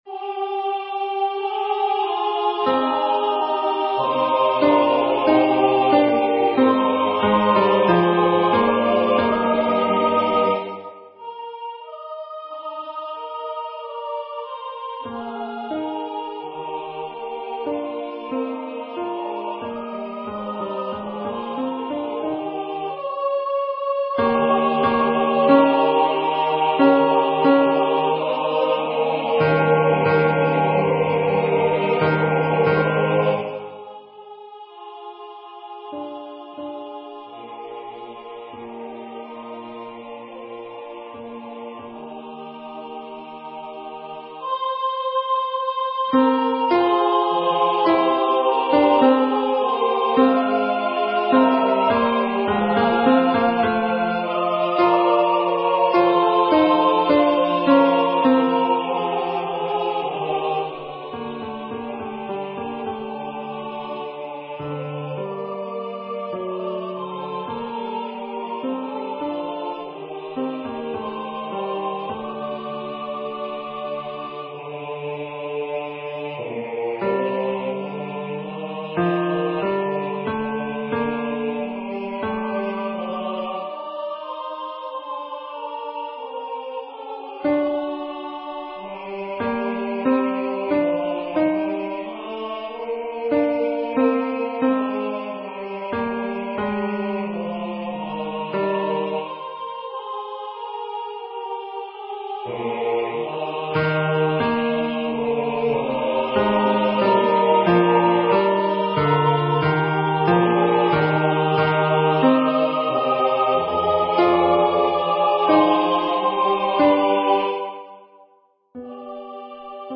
with Accompaniment
MP3 Practice Files: Soprano:   Alto:   Tenor:
Number of voices: 4vv   Voicing: SATB
Genre: SacredMotet
ChristusFactusEstHaydnTenrP.mp3